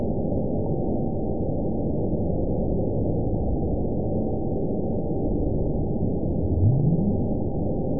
event 917803 date 04/16/23 time 23:46:35 GMT (2 years, 1 month ago) score 9.49 location TSS-AB04 detected by nrw target species NRW annotations +NRW Spectrogram: Frequency (kHz) vs. Time (s) audio not available .wav